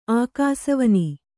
♪ ākāsavani